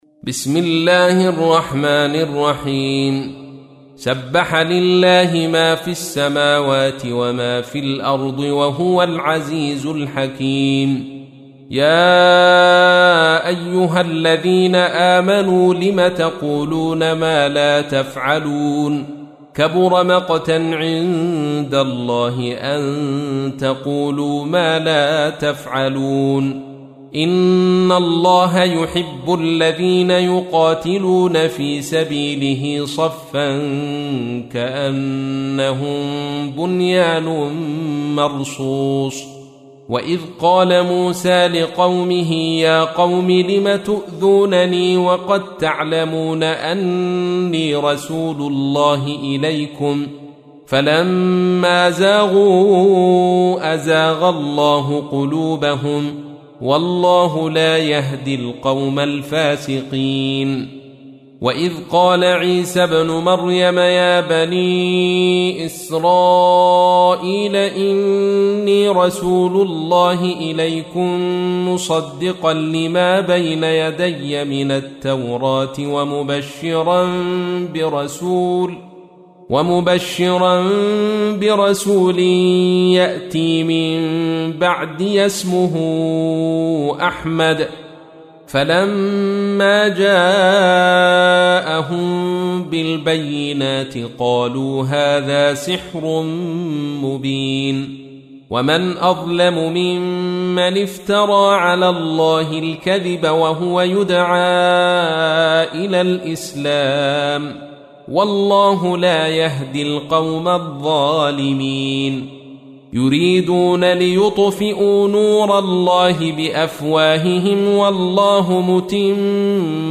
تحميل : 61. سورة الصف / القارئ عبد الرشيد صوفي / القرآن الكريم / موقع يا حسين